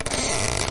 climb_rope.ogg